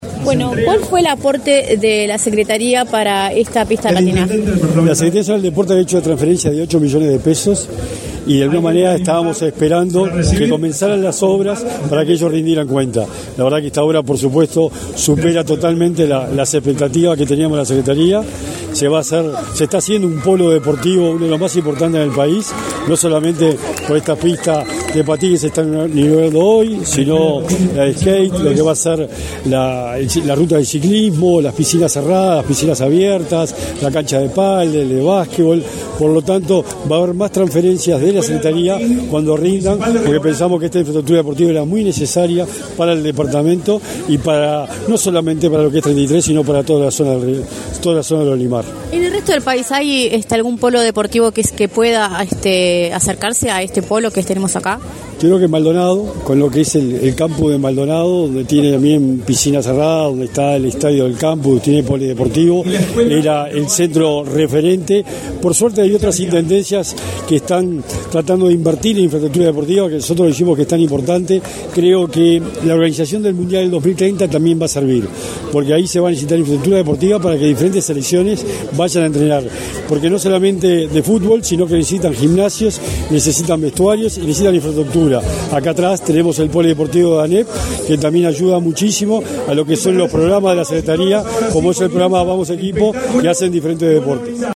Entrevista al titular de la Secretaría Nacional del Deporte, Sebastián Bauzá